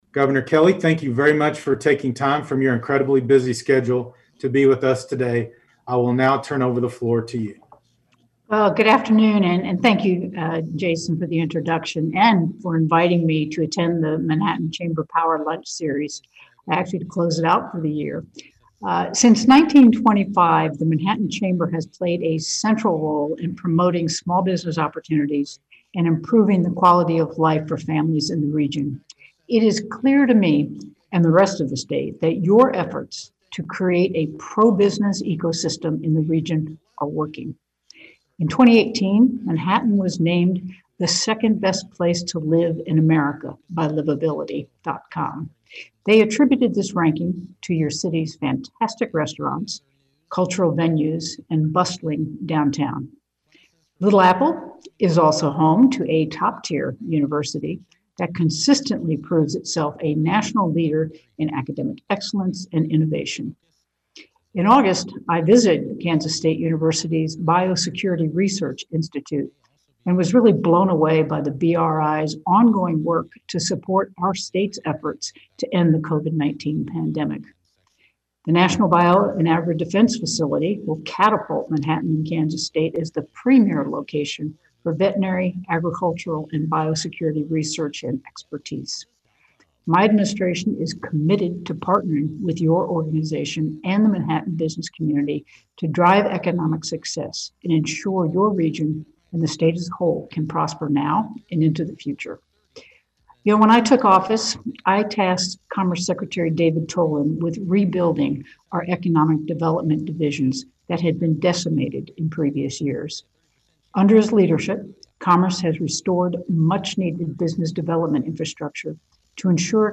The governor was the featured speaker at Tuesday’s Power Lunch, hosted virtually over Zoom by the Manhattan Area Chamber of Commerce.
1208-Governor-Laura-Kelly-at-MHK-Power-Lunch-.mp3